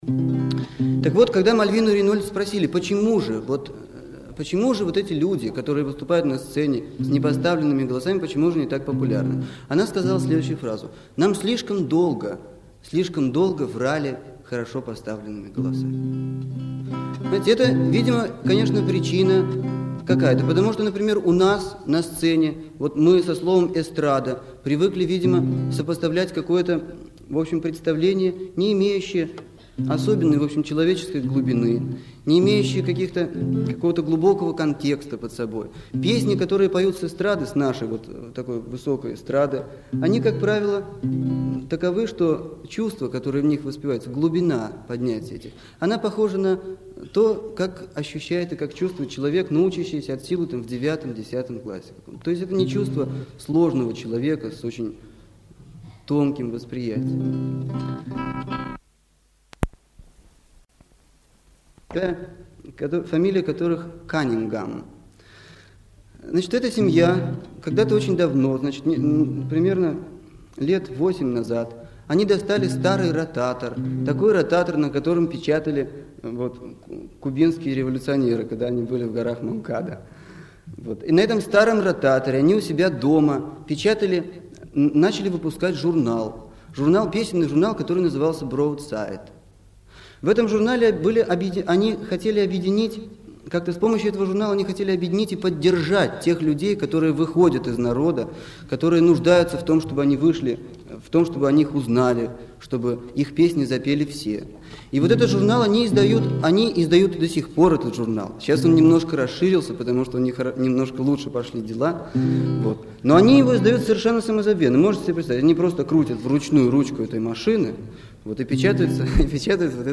в ленинградском клубе "Восток" 20 декабря 1967 года, посвящённый преимущественно американской "народной" песне